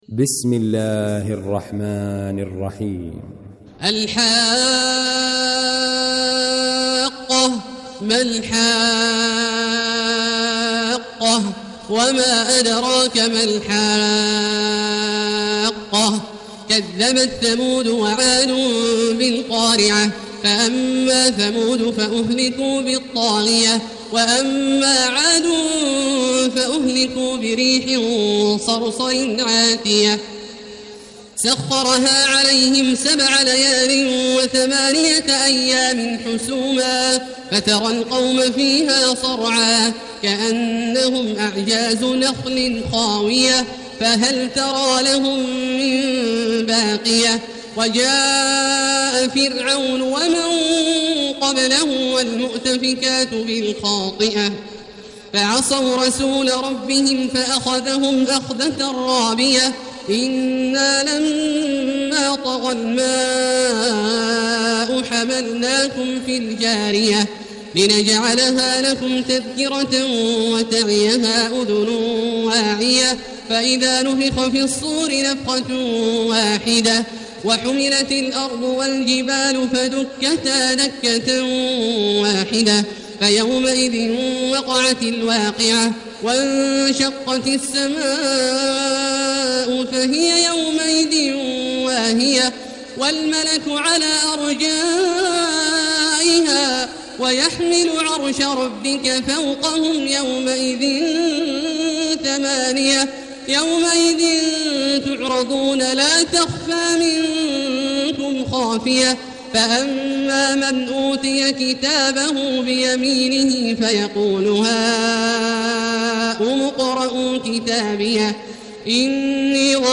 المكان: المسجد الحرام الشيخ: فضيلة الشيخ عبدالله الجهني فضيلة الشيخ عبدالله الجهني الحاقة